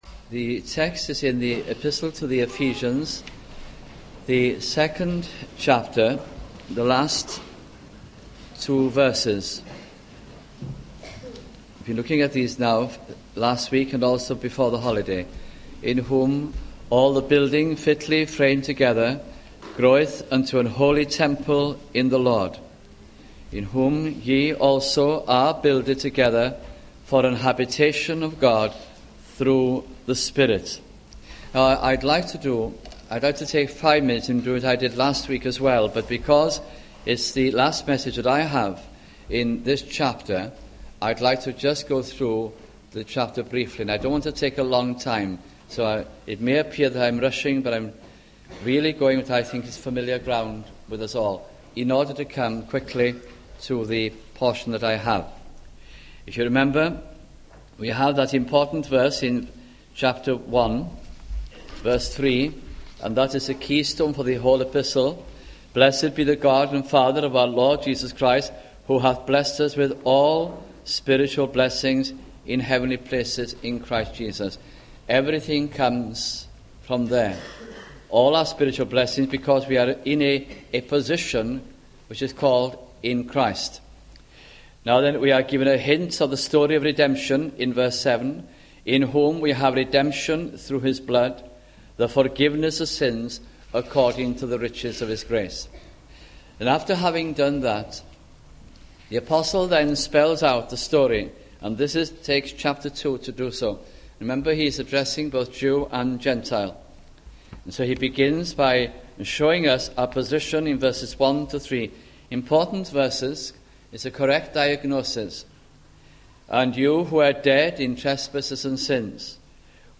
» Ephesians Series 1991 » sunday morning messages